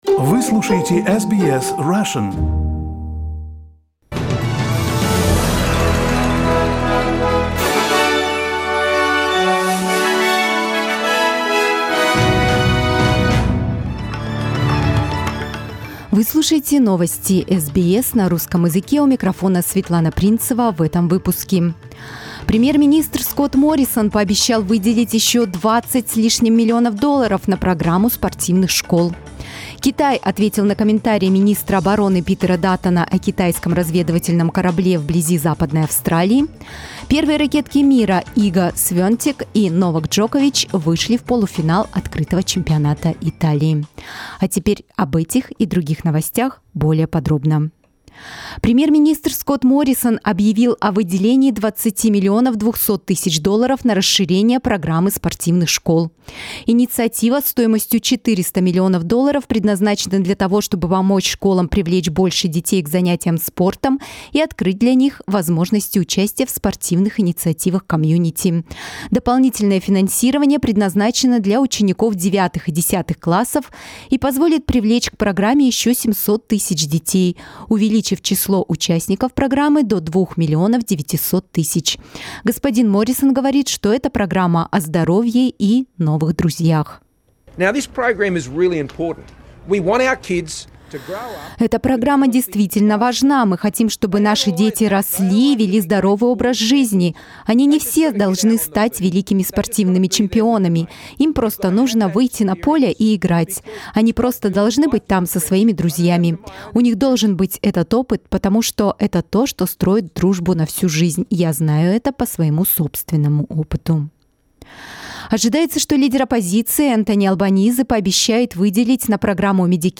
SBS News in Russian - 14.05.2022